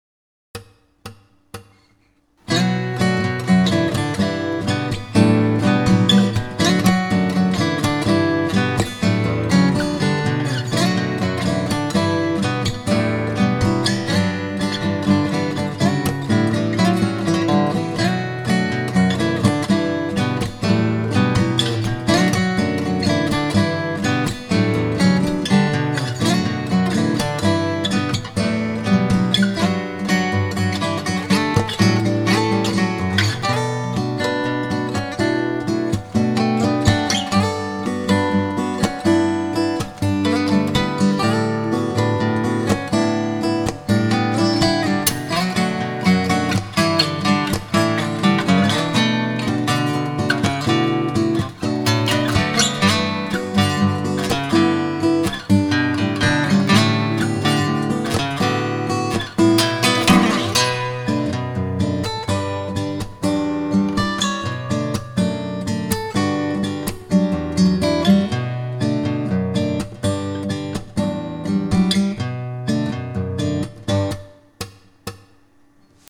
久々にアコギ曲など如何でしょうか？